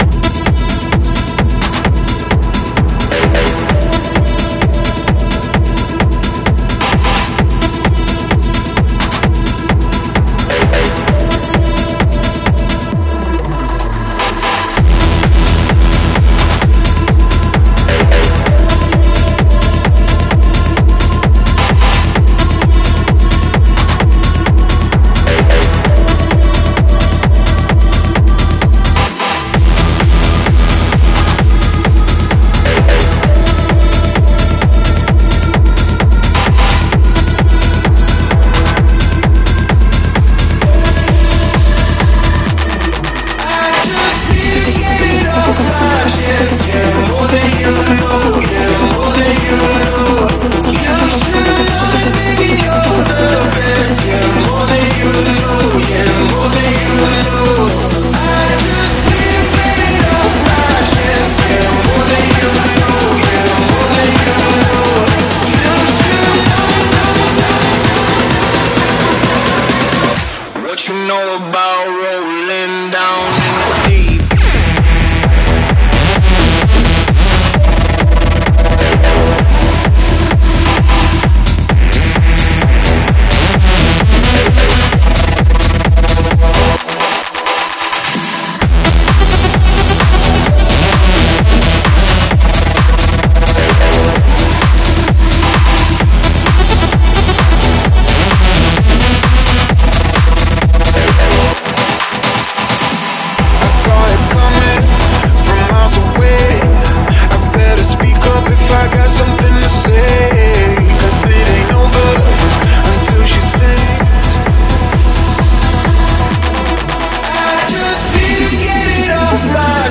试听文件为低音质，下载后为无水印高音质文件